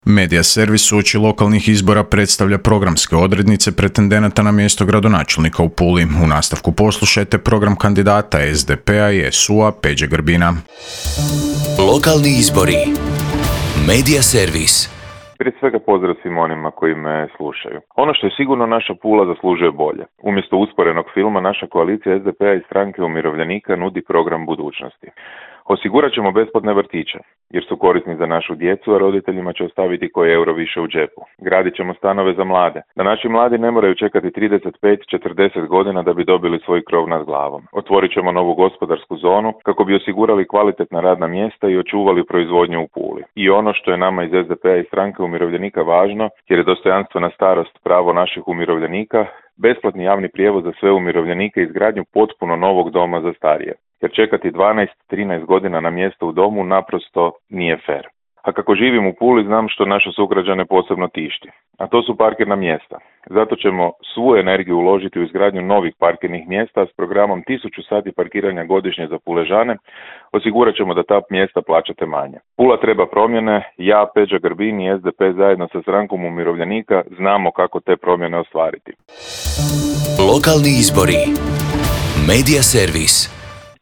PULA - Uoči lokalnih izbora kandidati za gradonačelnicu/gradonačelnika predstavljaju na Media servisu svoje programe u trajanju od 5 minuta. Kandidat za gradonačelnika Pule SDP-a i SU-a Peđa Grbin predstavio je građanima svoj program koji prenosimo u nastavku.